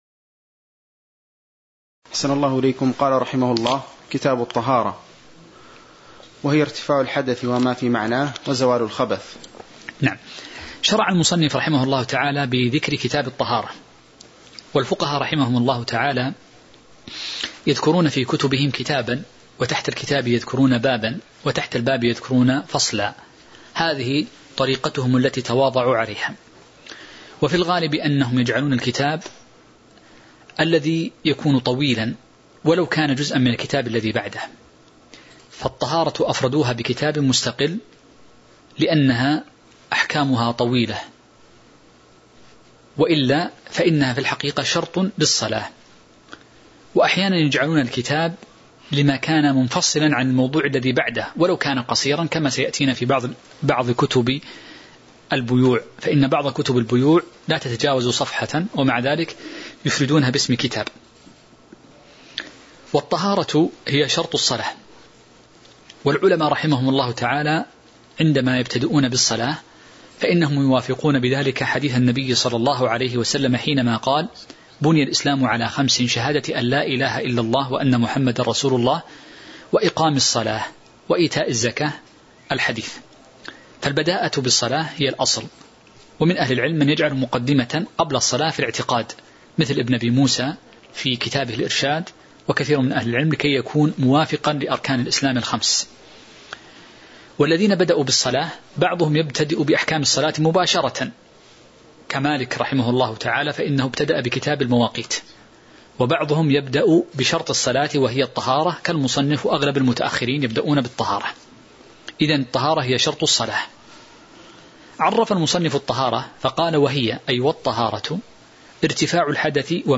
تاريخ النشر ١٦ ذو الحجة ١٤٤٢ هـ المكان: المسجد النبوي الشيخ